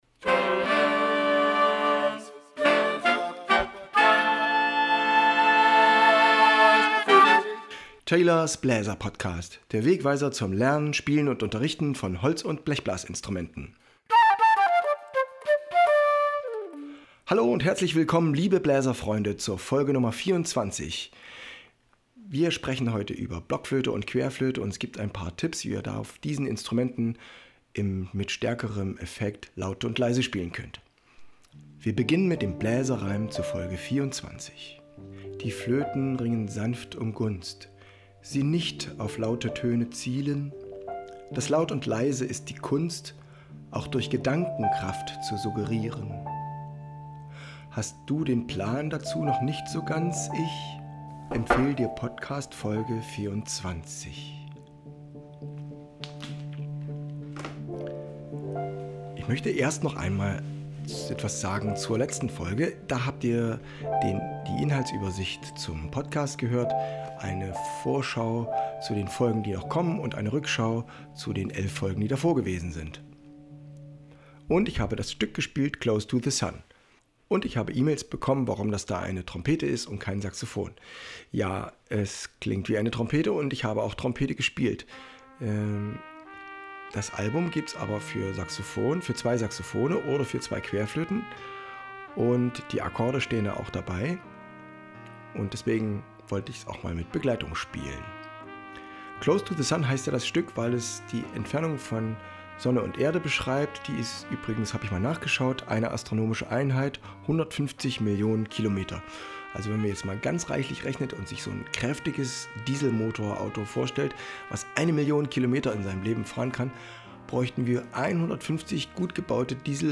BONUSMUSIK: Es dunkelt schon in der Heide, Volkslied, "Quell des Lebens" जीवन का स्रोत Ausschnitt aus dem Stück für den World Music Day am 21.6. im Auftrag für Kolkata, Indien.